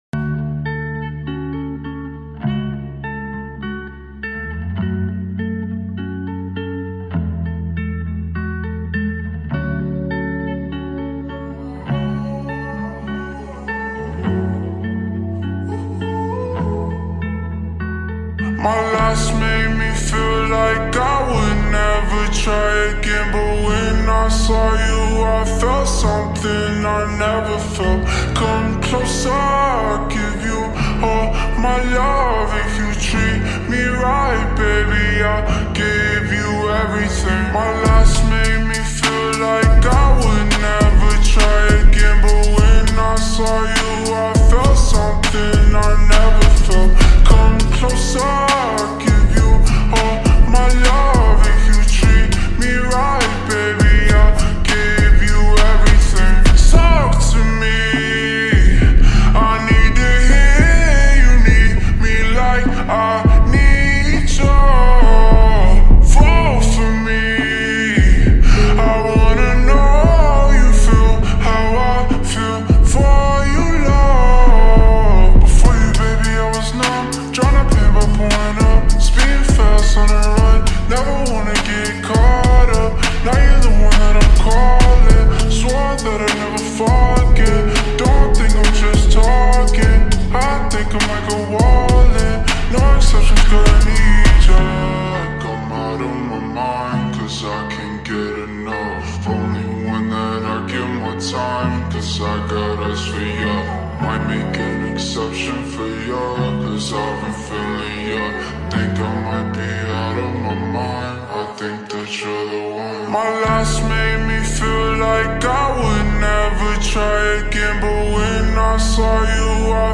با ریتمی کند شده
غمگین
عاشقانه